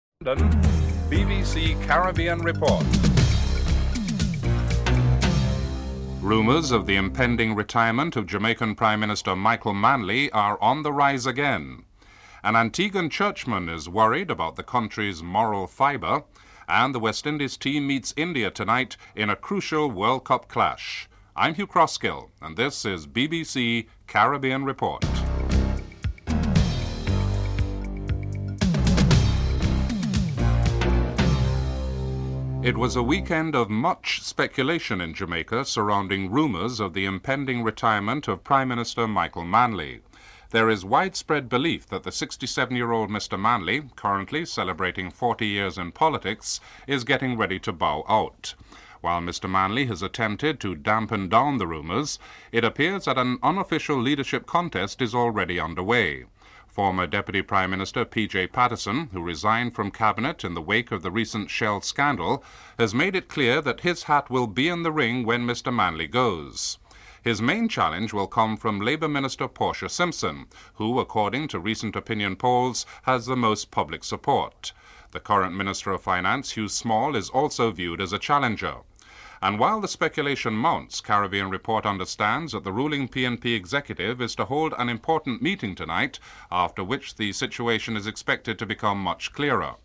The British Broadcasting Corporation
1. Headlines (00:00-00:31)